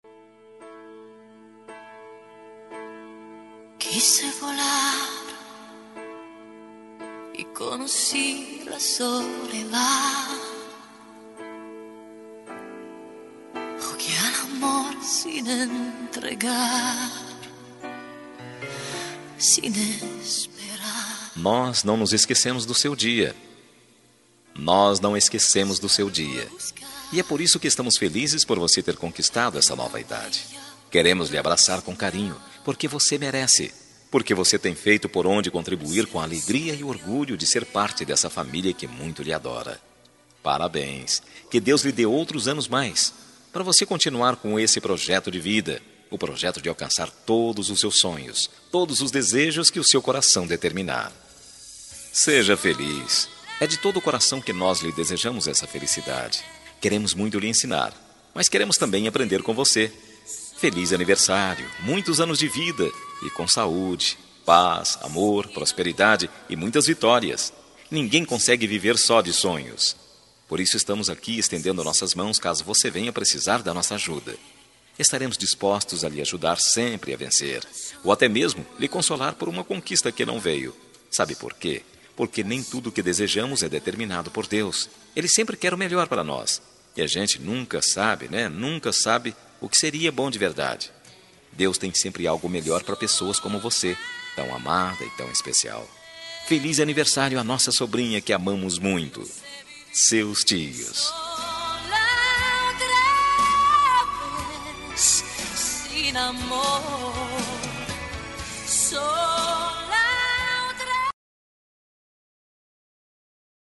Aniversário de Sobrinha – Voz Masculina – Cód: 2165